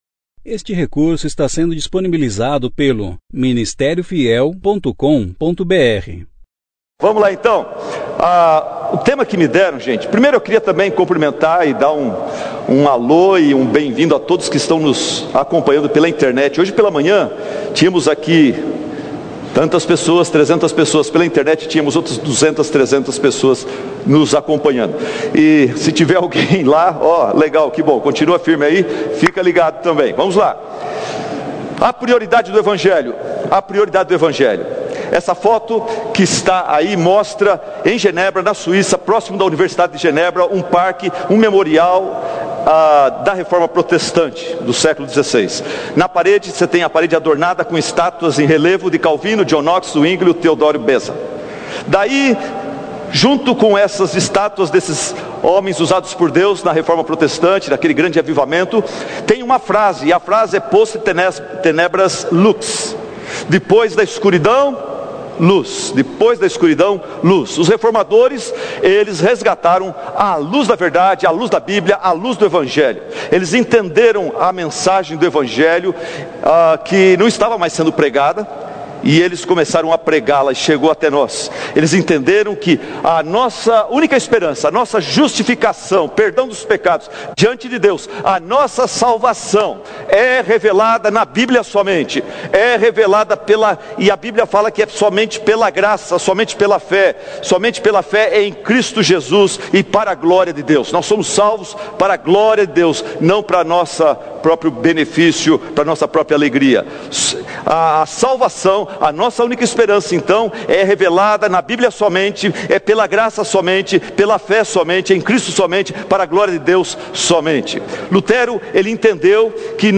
Conferência: Juntos em Cristo – Goiânia Tema: Protestantes Ano: 2017 Mensagem: A Prioridade do Evang